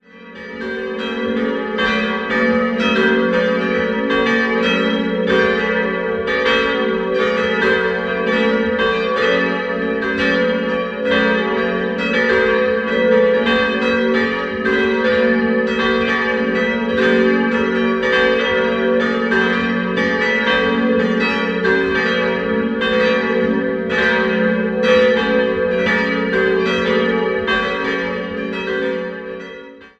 4-stimmiges Geläut: fis'-gis'-ais'-cis''